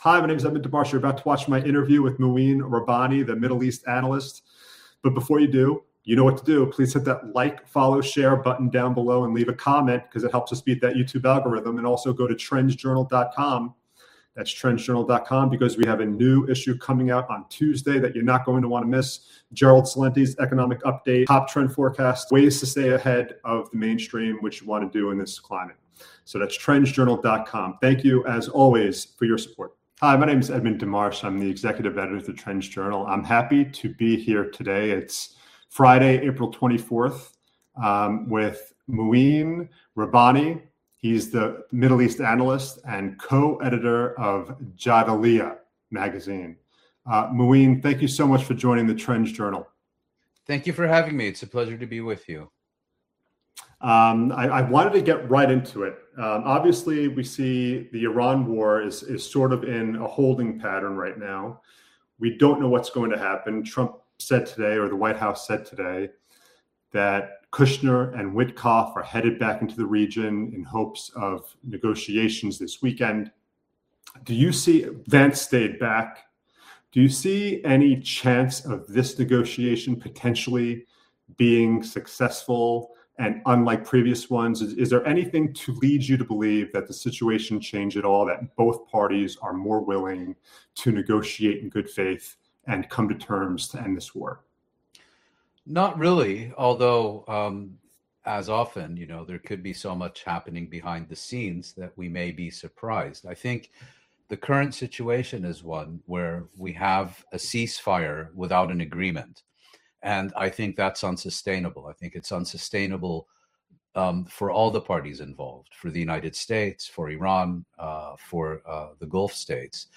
In a revealing interview